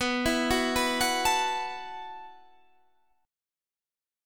B7sus4 Chord